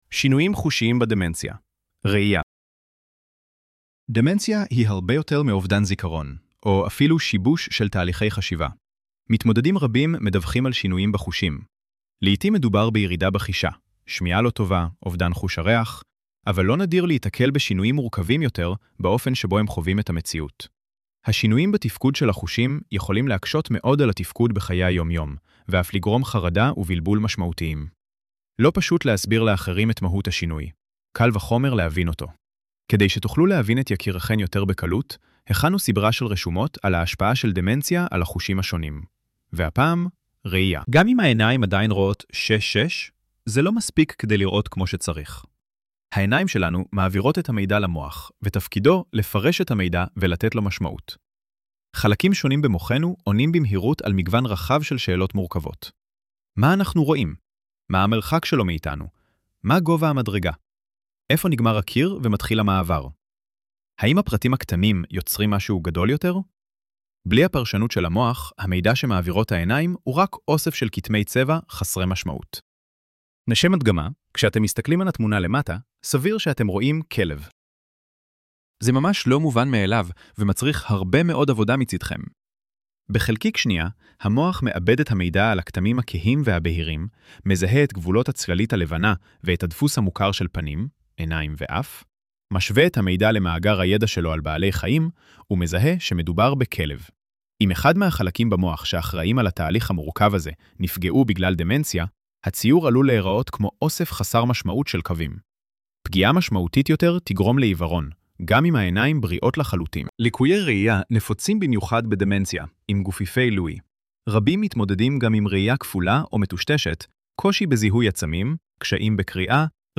ElevenLabs_שינויים_חושיים_בדמנציה_-_ראייה-2.mp3